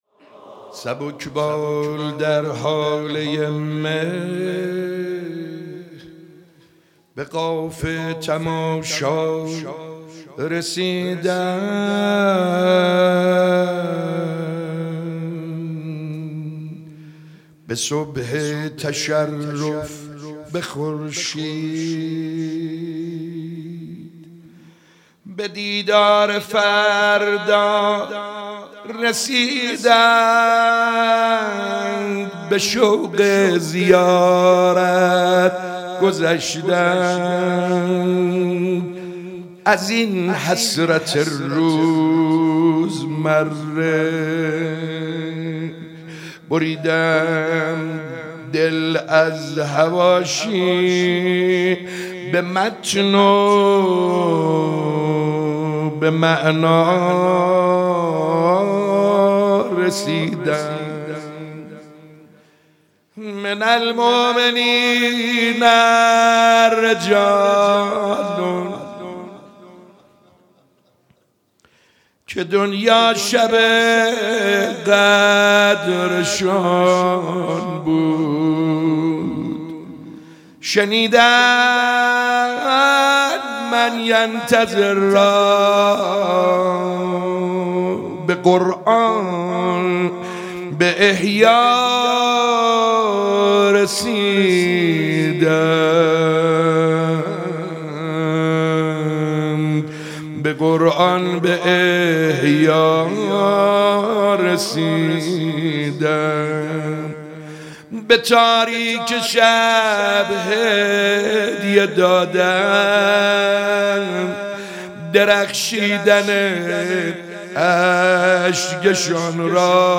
مداحی سبک بال در هاله ی مه به قاف تماشا رسیدن توسط حاج سعیدحدادیان در مجلس هیئت فدائیان حضرت زهرا (س) | 8 خرداد | 1403 اجراشده. مداحی به سبک روضه اجرا شده است.